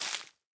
Minecraft Version Minecraft Version latest Latest Release | Latest Snapshot latest / assets / minecraft / sounds / block / leaf_litter / break5.ogg Compare With Compare With Latest Release | Latest Snapshot